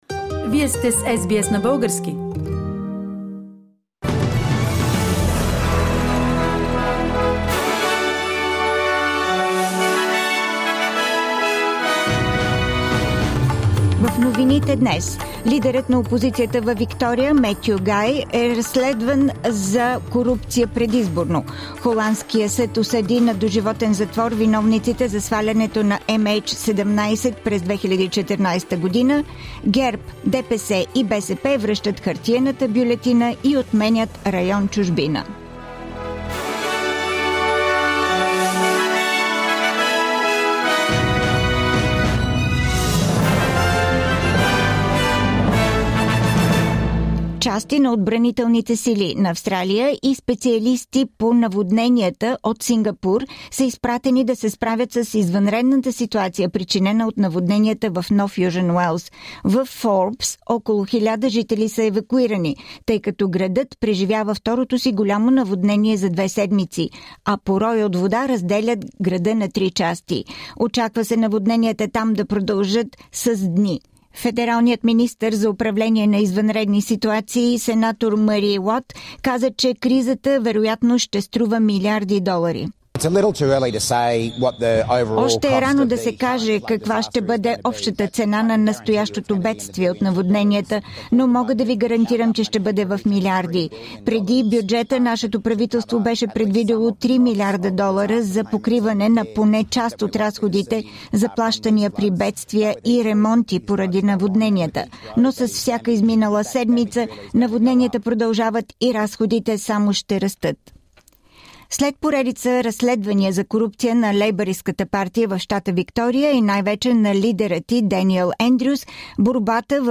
Радио SBS новини на български език – 18-ти ноември 2022
Седмичен преглед на новините